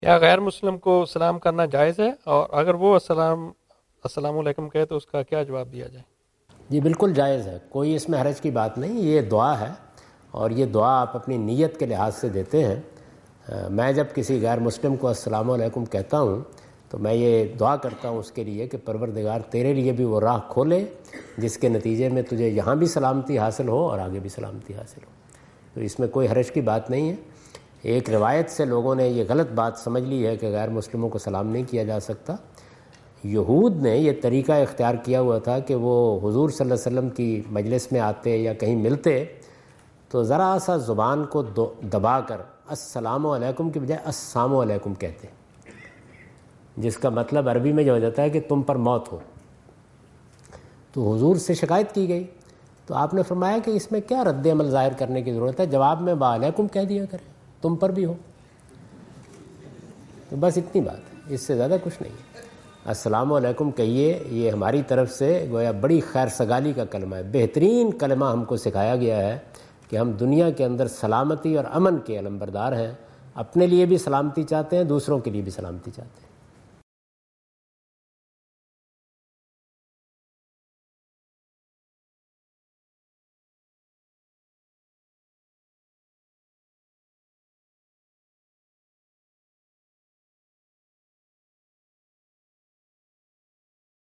Javed Ahmad Ghamidi answer the question about "responding to greetings of non-Muslims" in Macquarie Theatre, Macquarie University, Sydney Australia on 04th October 2015.